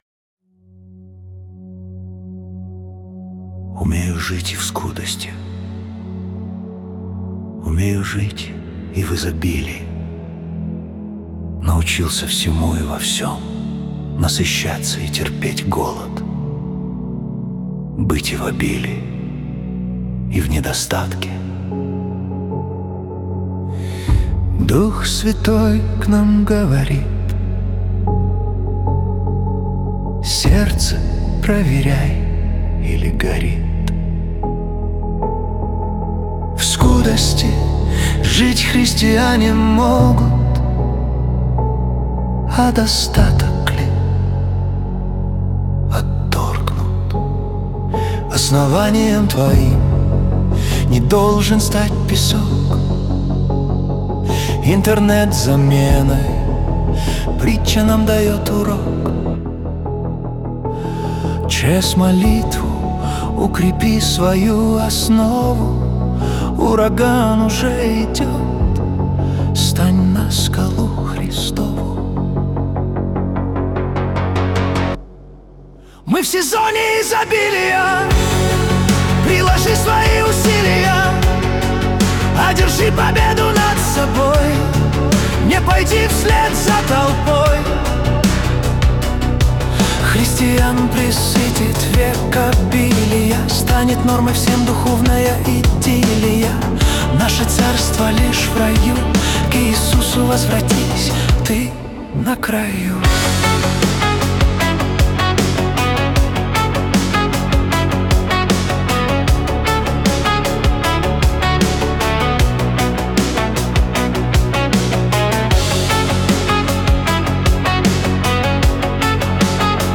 песня ai
128 просмотров 671 прослушиваний 41 скачиваний BPM: 77